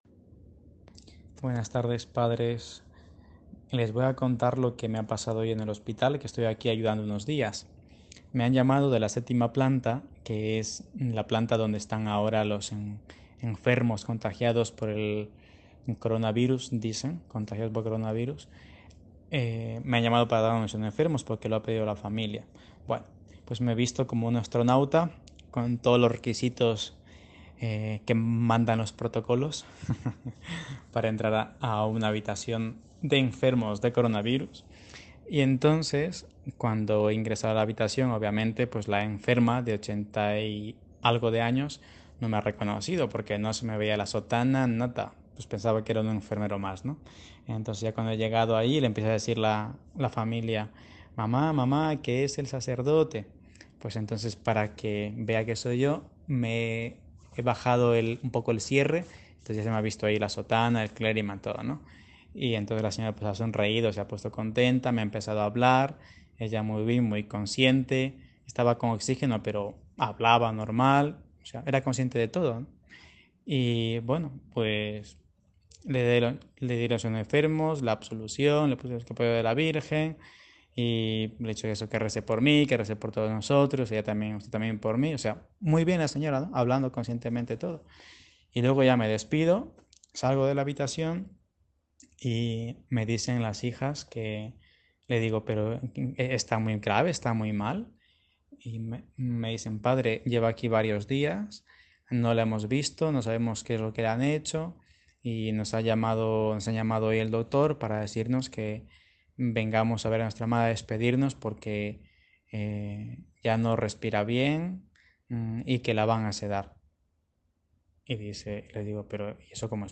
EN ESPAÑA SACERDOTE DENUNCIA LO QUE ESTÁ OCURRIENDO CON LOS ANCIANOS EN LOS HOSPITALES AL LLEVARLES EL SACRAMENTO DE LA EXTREMAUNCIÓN.